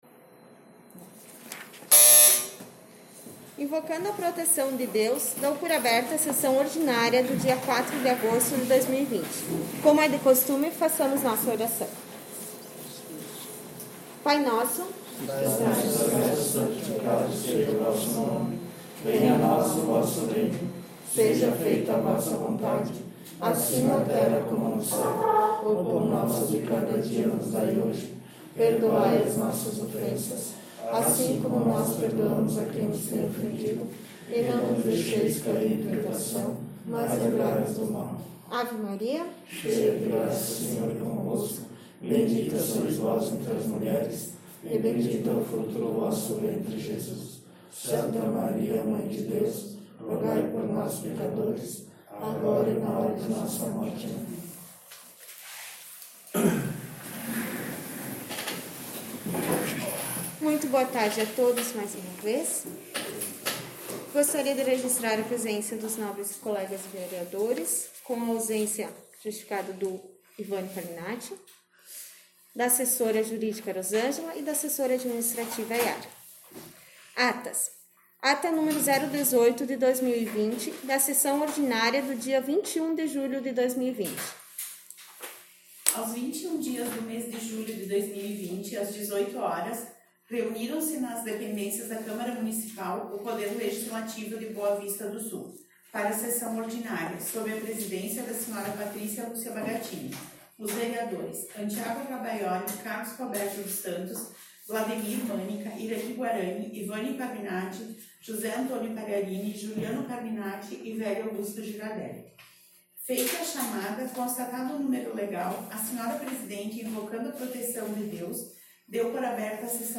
Sessão Ordinária 04/08/2020